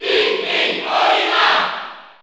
Olimar_Cheer_French_NTSC_SSBU.ogg.mp3